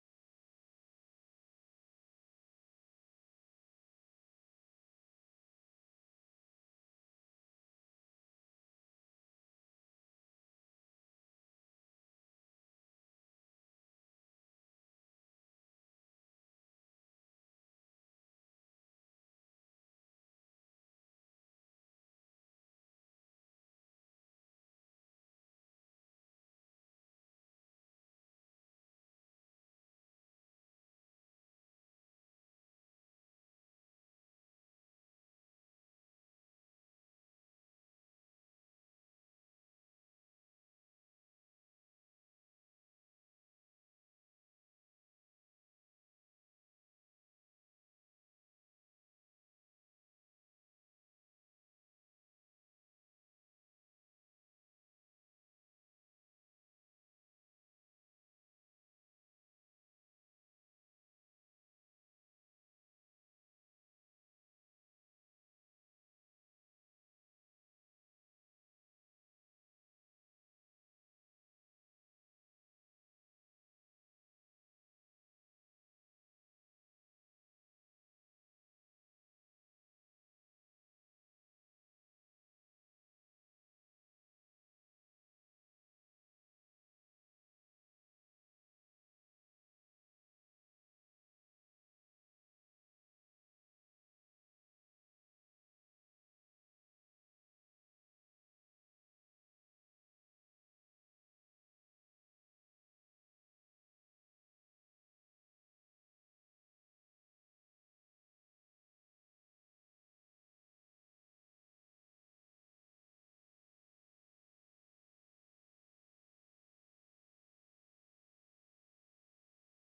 Jig (G major)
played slow